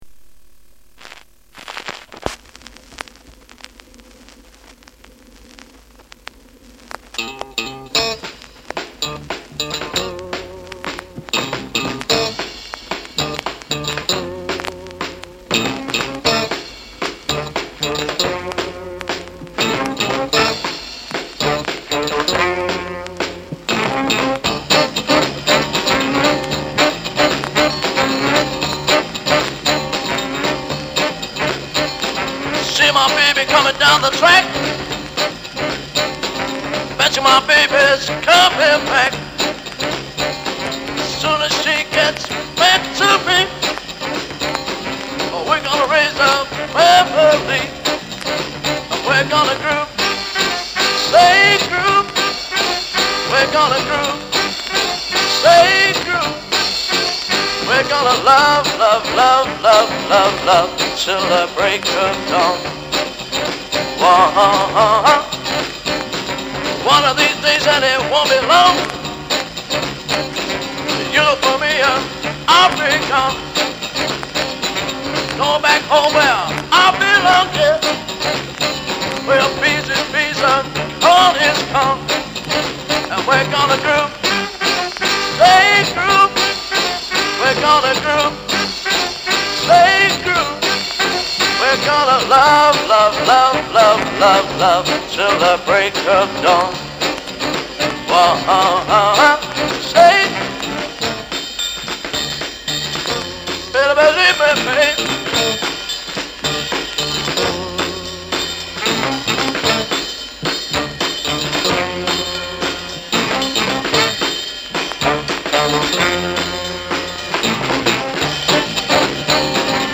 lead vocals
lead guitar
bass
tenor saxophone
drums